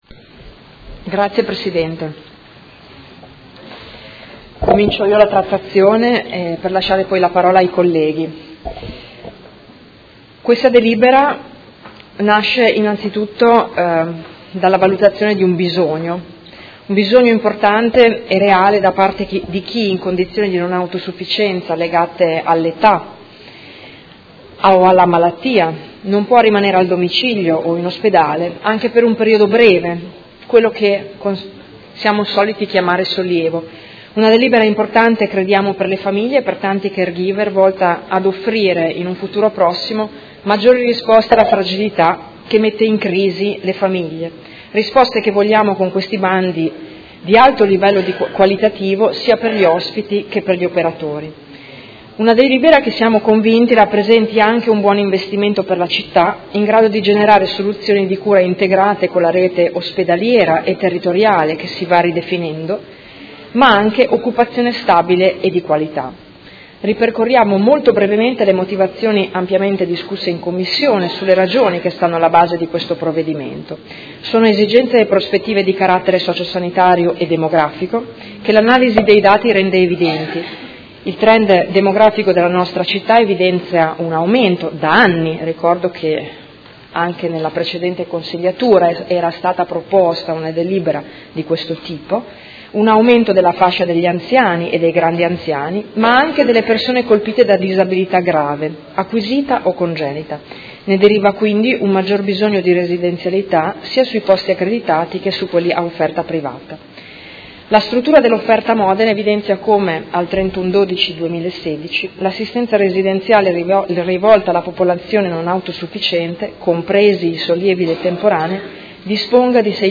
Giuliana Urbelli — Sito Audio Consiglio Comunale